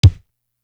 Sun Kick.wav